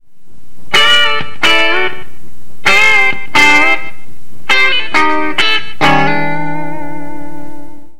热情的布吉在一个小村庄
描述：一个大的开放性的布吉。更多的牛铃 400赫兹的低中音增强，非常轻微的延迟。 这是我正常的 "现场 "声音。
Tag: 120 bpm Country Loops Guitar Electric Loops 689.64 KB wav Key : A